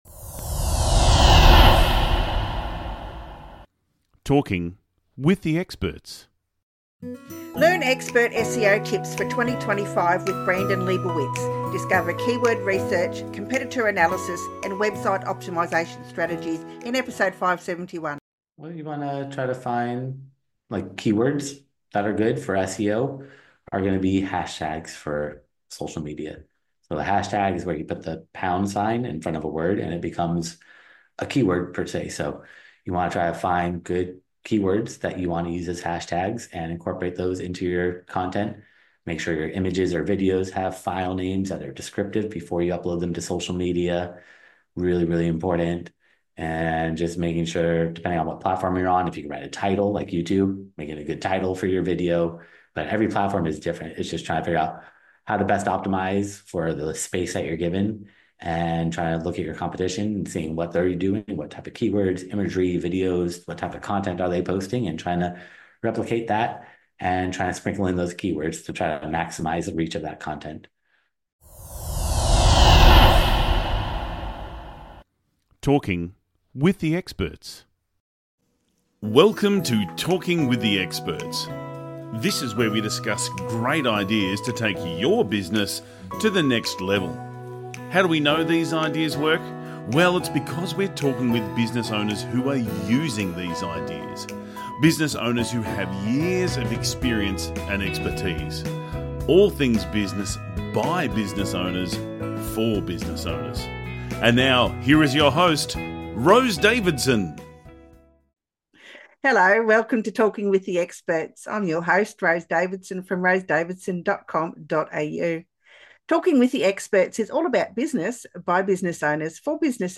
In this insightful interview